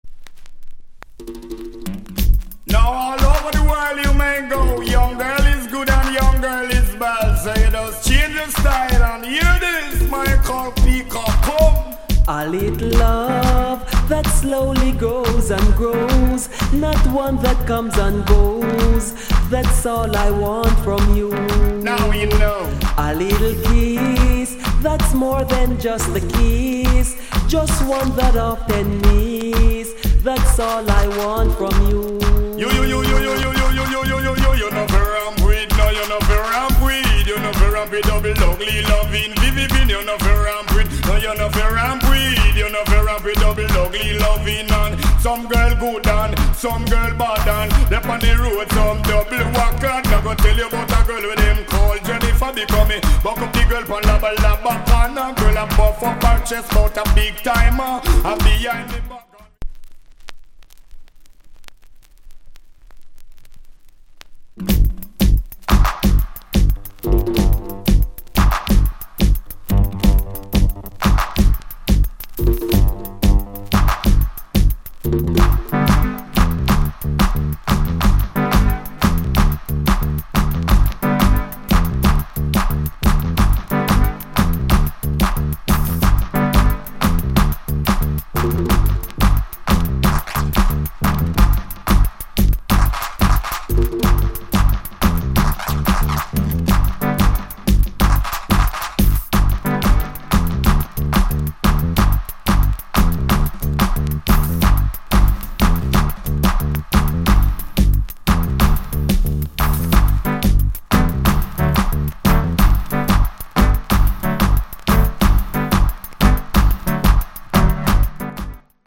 Dee-Jay